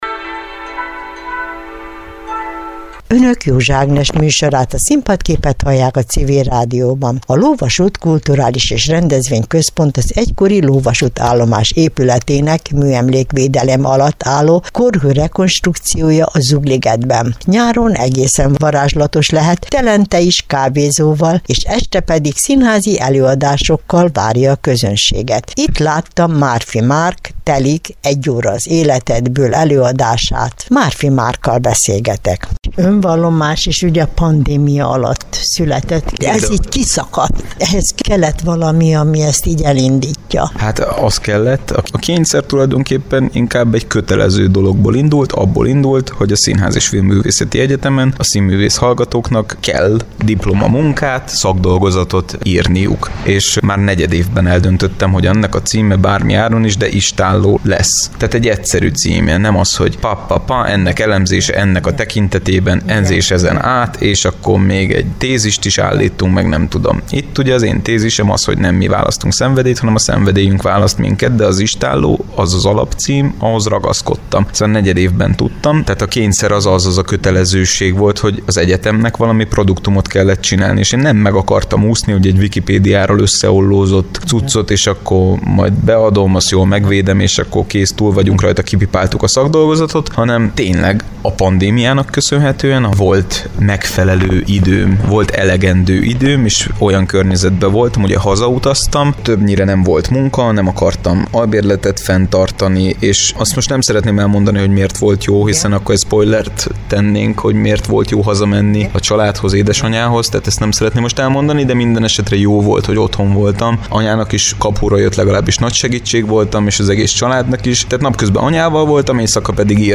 A Telik monodráma